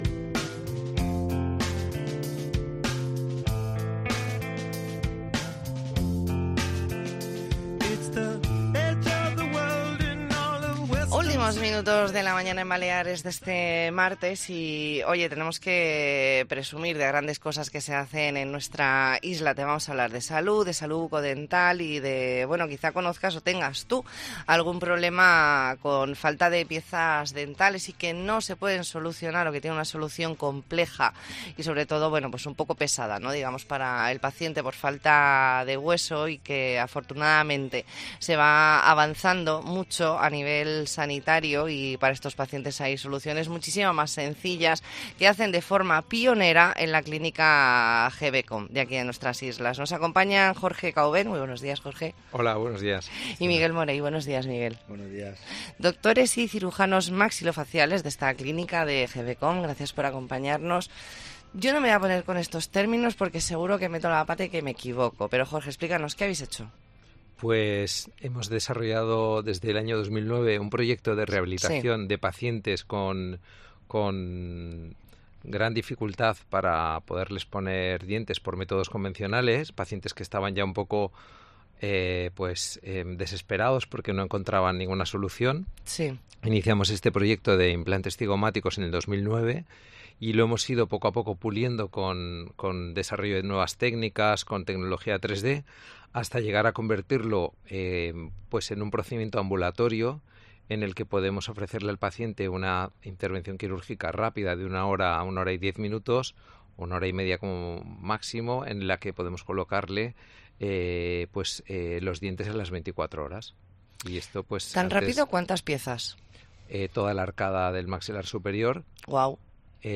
Entrevista en La Mañana en COPE Más Mallorca, martes 24 de octubre de 2023.